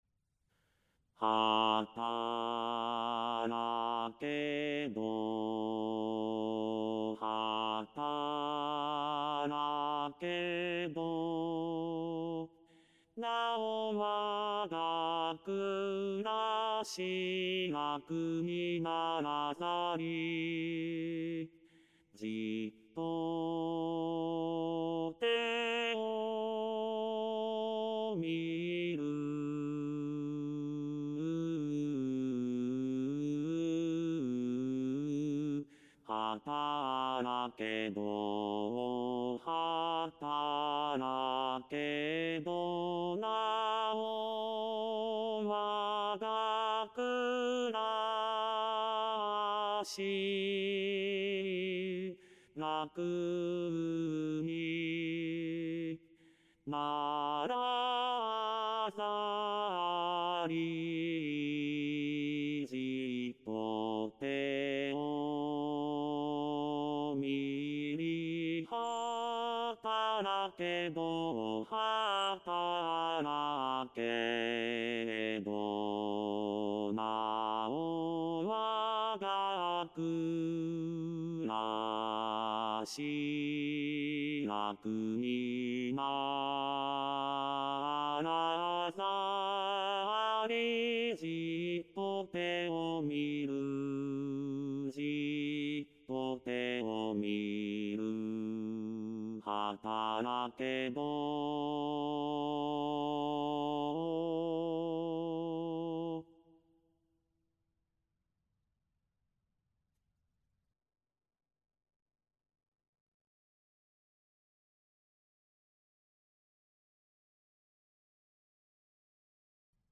●パート単独音源　　　■Rchソプラノ、Lch、アルト、テノール、バス
hatarakedo_bassueonly.mp3